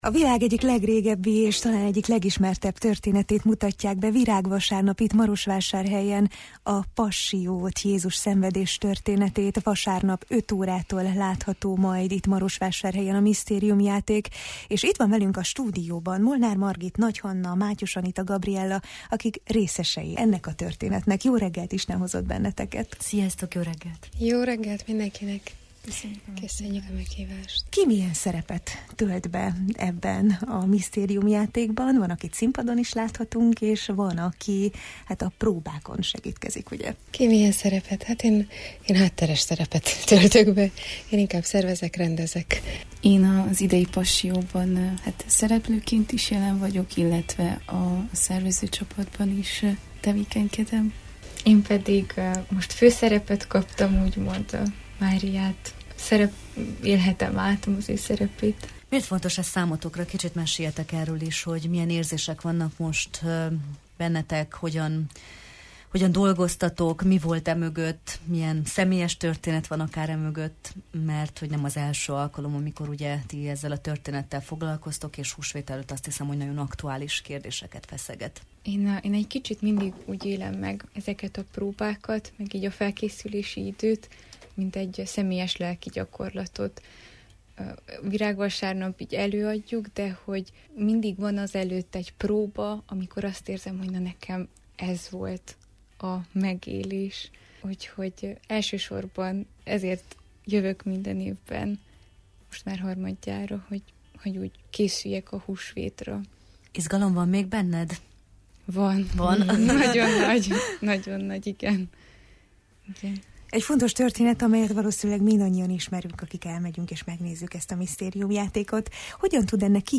voltak a vendégeink a misztériumjáték kapcsán: